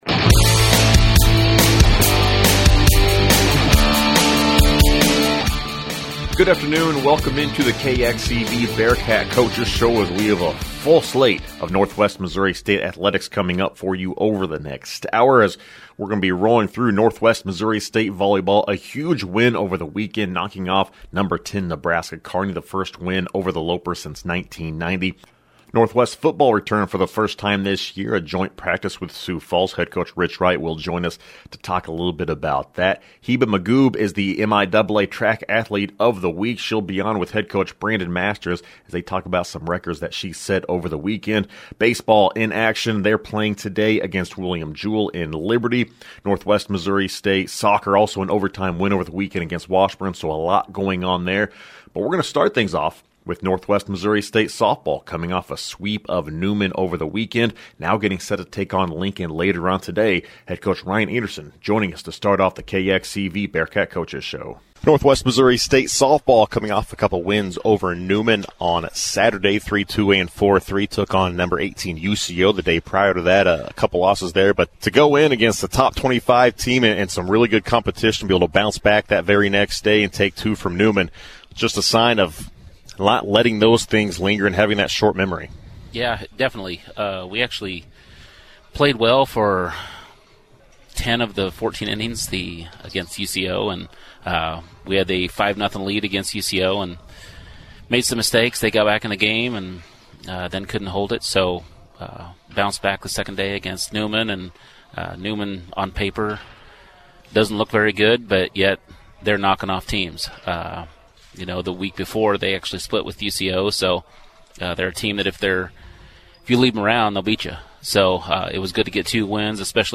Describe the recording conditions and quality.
KXCV-KRNW is the public radio service of Northwest Missouri State University, Maryville.